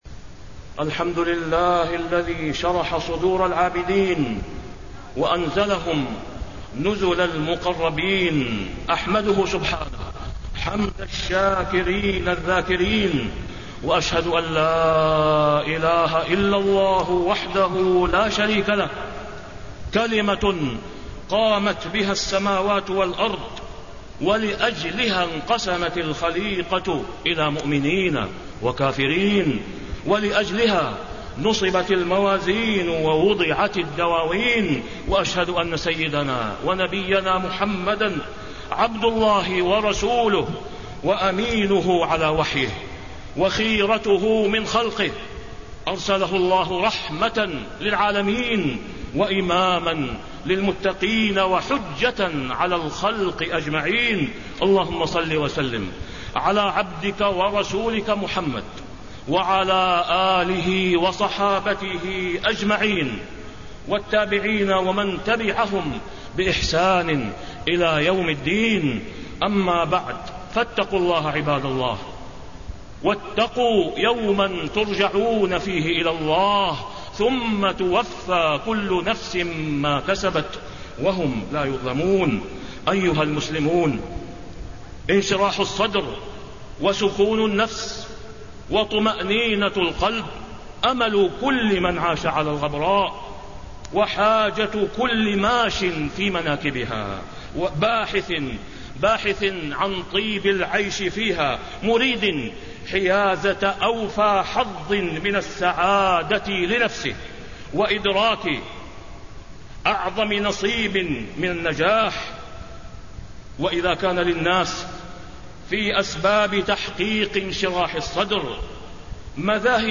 تاريخ النشر ٢٧ جمادى الآخرة ١٤٣٣ هـ المكان: المسجد الحرام الشيخ: فضيلة الشيخ د. أسامة بن عبدالله خياط فضيلة الشيخ د. أسامة بن عبدالله خياط أسباب إنشراح الصدر